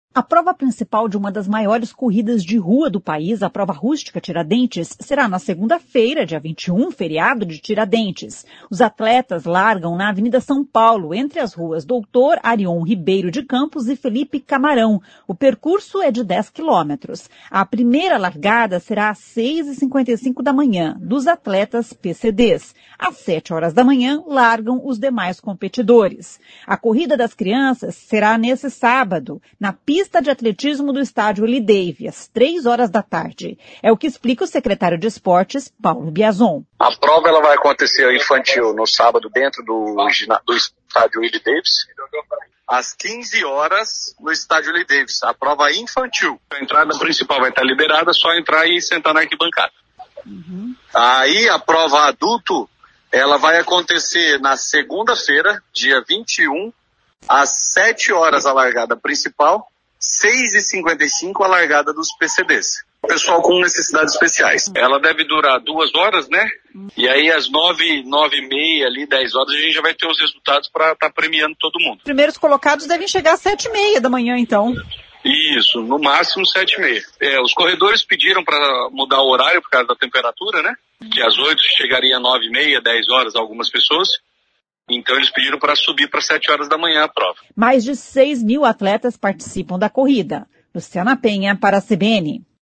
É o que explica o secretário de Esportes, Paulo Biazon.